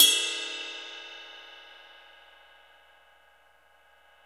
CYM XRIDE 5B.wav